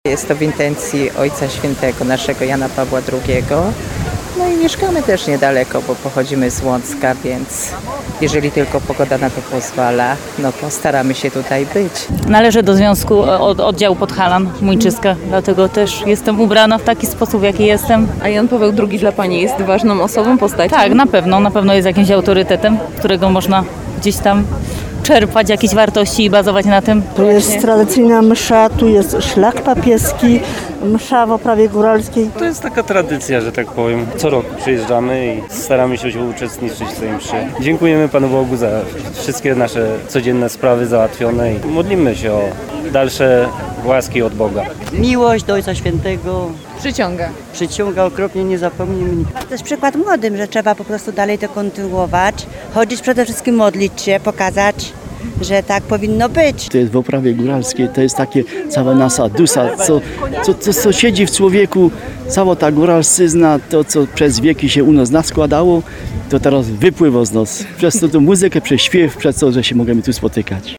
Tłumy wiernych spotkały się na Cisowym Dziale. Przy kaplicy pw. św. Jana Pawła II odprawiona została coroczna lipcowa Msza św. w oprawie góralskiej.